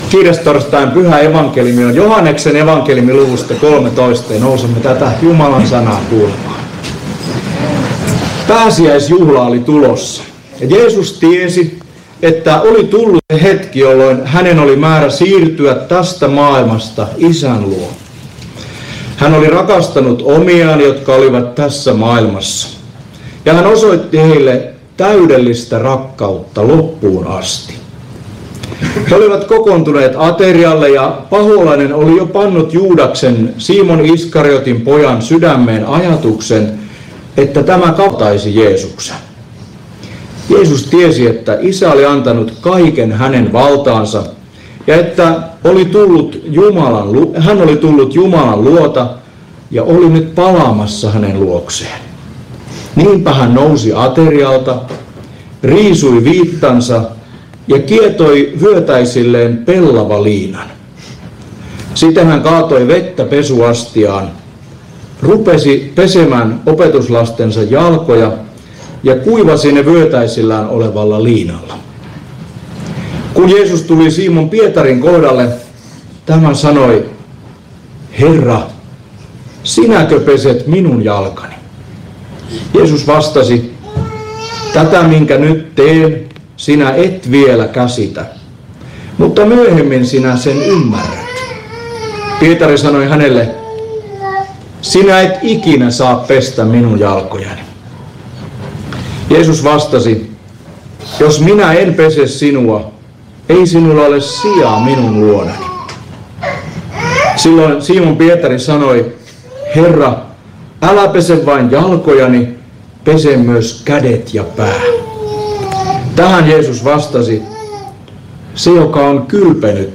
Porissa kiirastorstaina Tekstinä Joh. 13:1–15